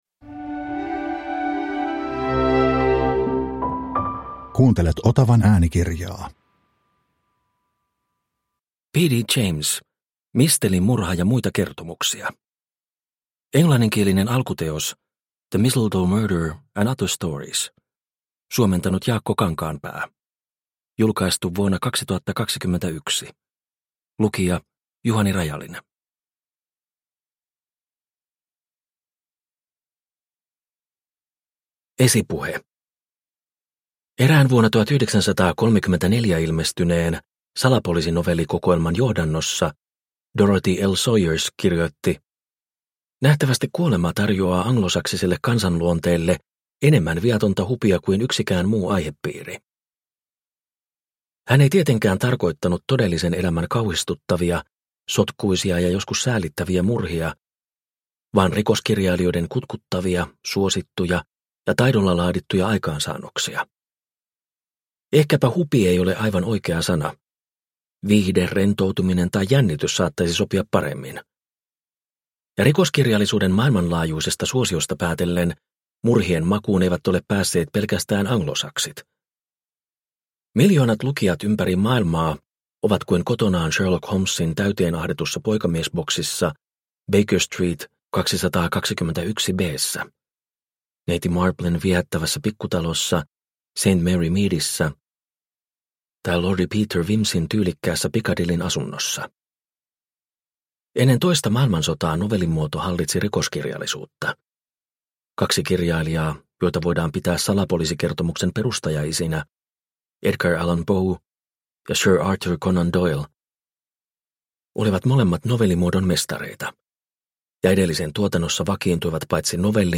Mistelimurha ja muita kertomuksia – Ljudbok – Laddas ner